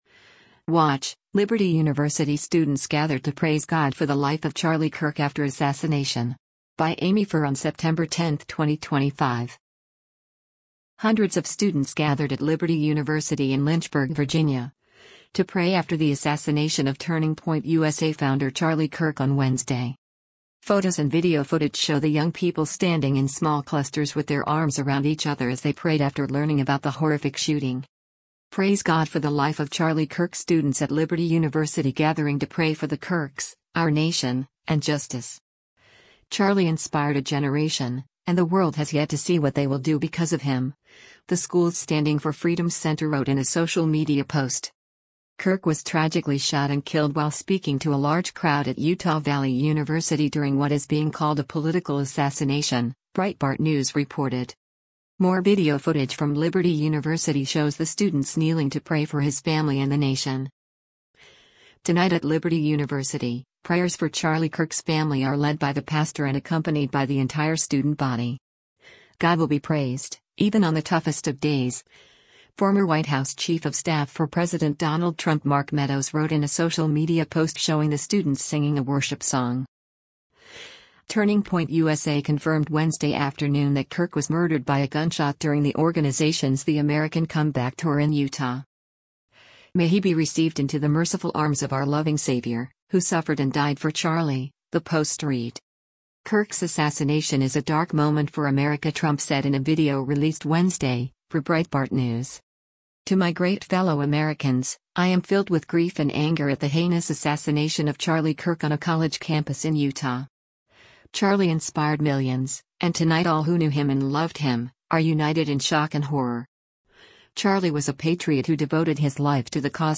Hundreds of students gathered at Liberty University in Lynchburg, Virginia, to pray after the assassination of Turning Point USA founder Charlie Kirk on Wednesday.